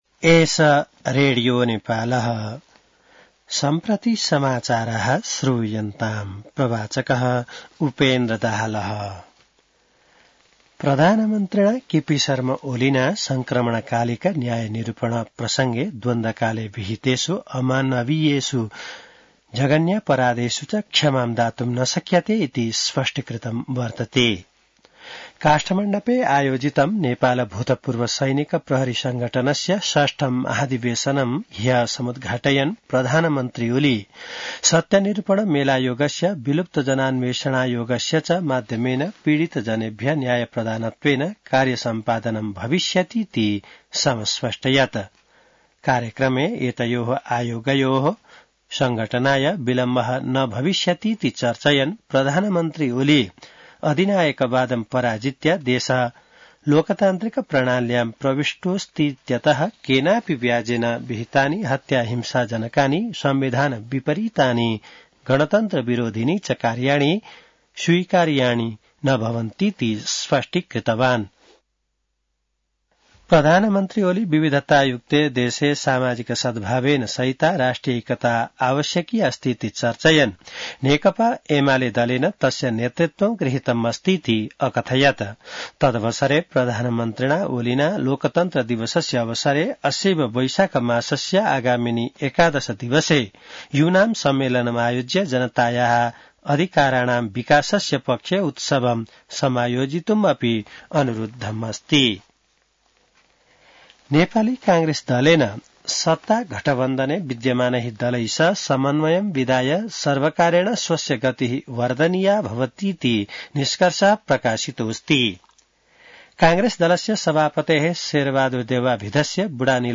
संस्कृत समाचार : ५ वैशाख , २०८२